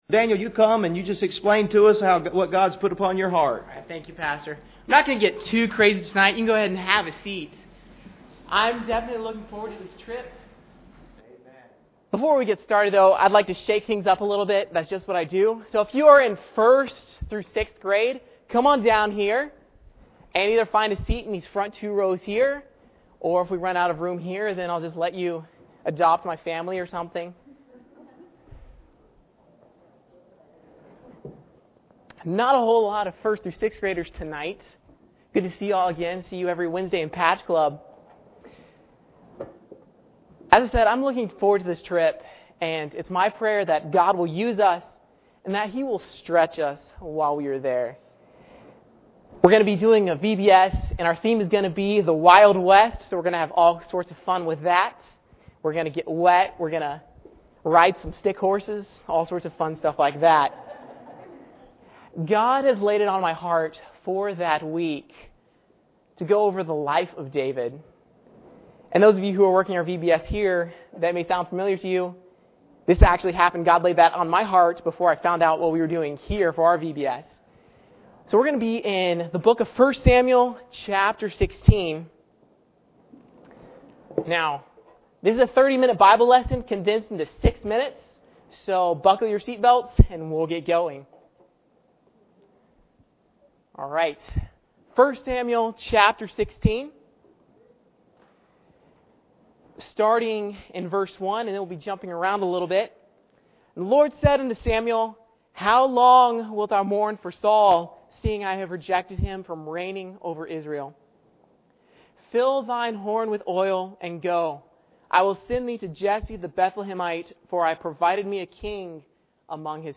Bible Text: Philippians 2:25 | Preacher: CCBC Members | Series: General
Service Type: Sunday Evening